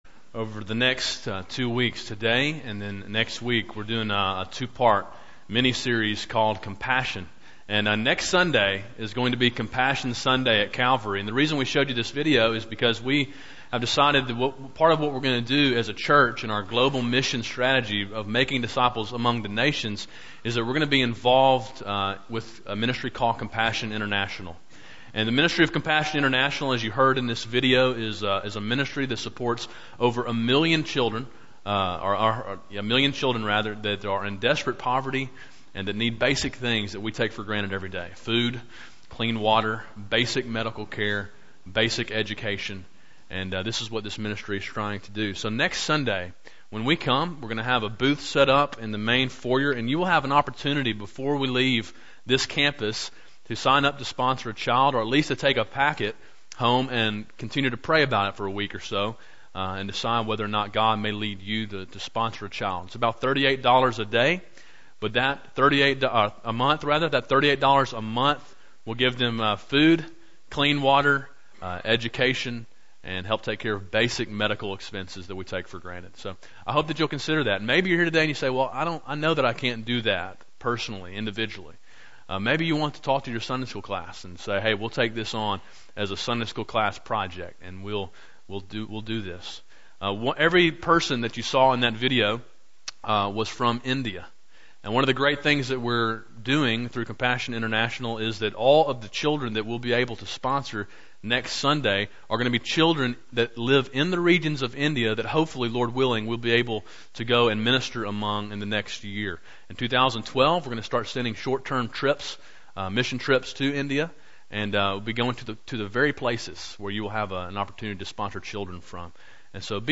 A sermon in a two part series called Compassion. Main point: To be a compassionate church we must know the compassion of our God.